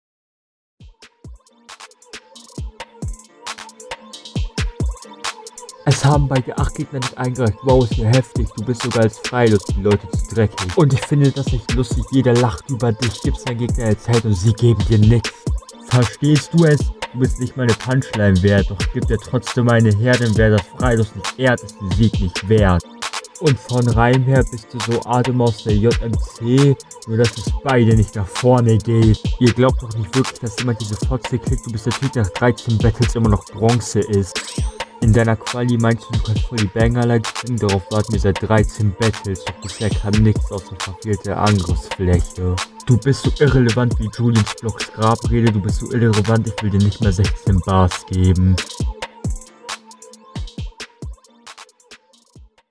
Wieder das Gleiche: Du rappst nicht, du redest.